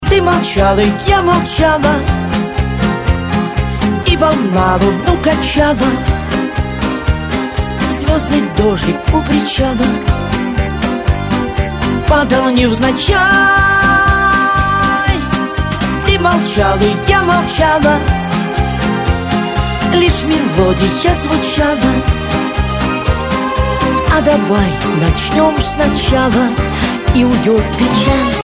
Шансон, Авторская и Военная песня